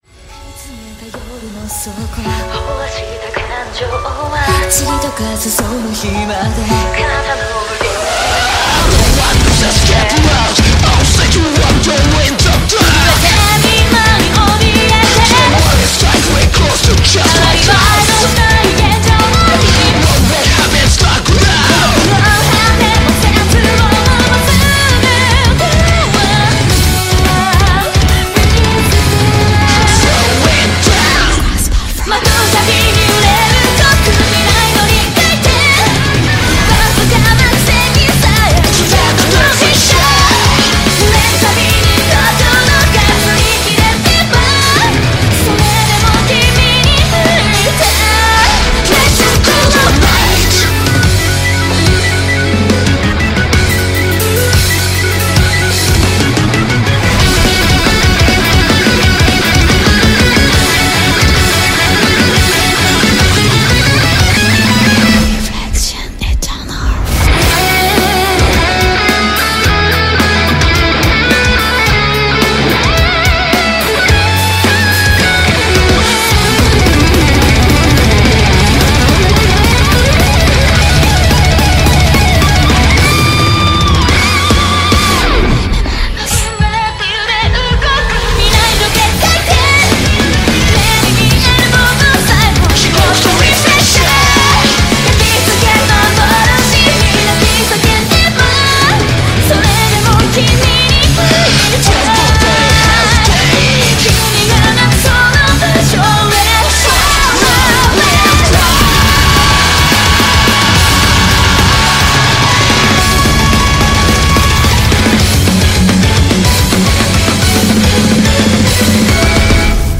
BPM108-216
Audio QualityPerfect (Low Quality)